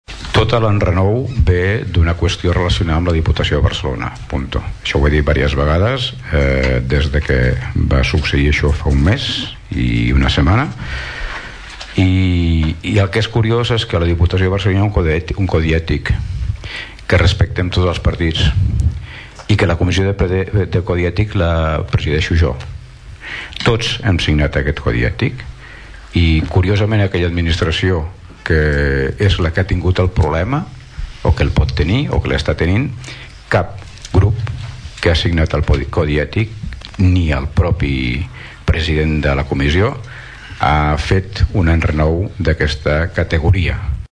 El ple de l’Ajuntament va debatre ahir una moció presentada pel grup municipal de Som Tordera-Entesa en defensa dels drets polítics i civils de les persones, la democràcia i la llibertat d’expressió i en contra de les conductes que vulnerin aquests principis fonamentals.
L’Alcalde de Tordera, Joan Carles Garcia va recordar que la moció sorgeix d’un estat emocional del regidor de Som Tordera i que no és pertinent.